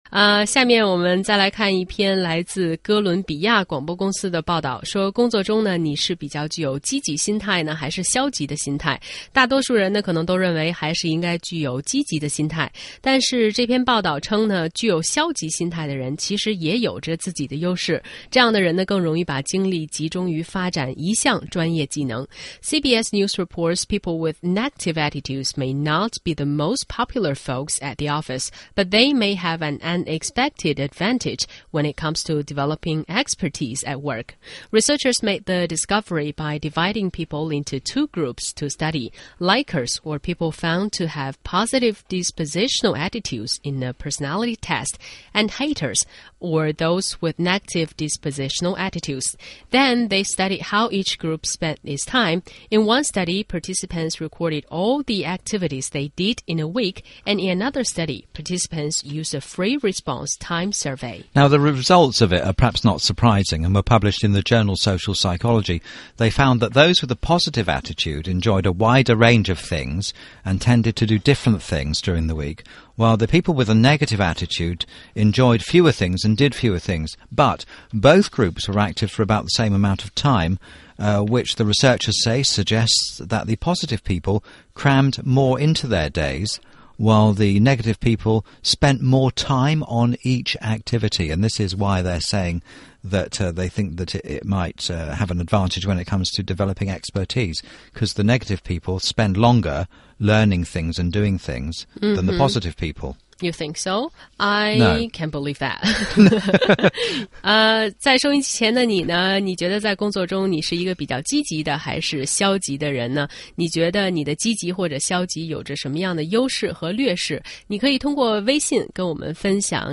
在线英语听力室双语趣听精彩世界 第8期:消极的心态也有优势的听力文件下载,《双语趣听精彩世界》栏目通过讲述中外有趣的故事，来从不同的角度看中国、看世界，是了解大千世界的极好材料。中英双语的音频，能够帮助提高英语学习者的英语听说水平，中外主持人的地道发音，是可供模仿的最好的英语学习材料,可以帮助英语学习者在轻松娱乐的氛围中逐渐提高英语学习水平。